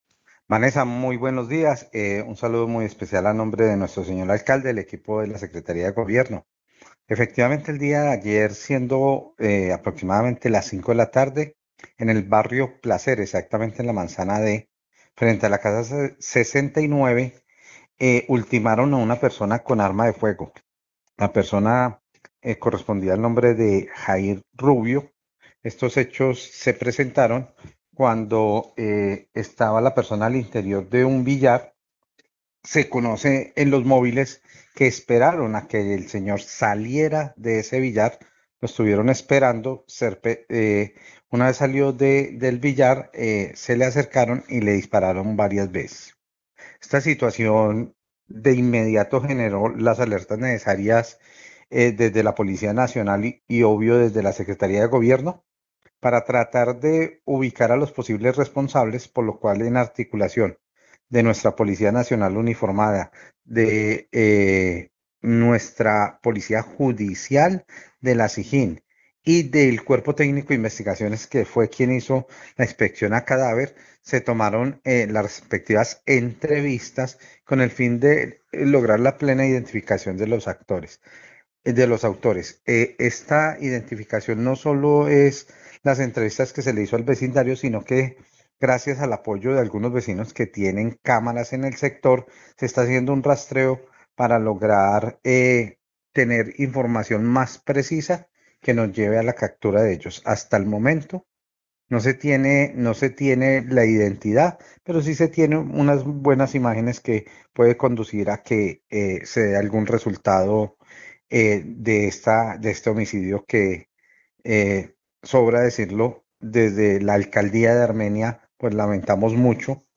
Secretario de Gobierno de Armenia sobre homicidio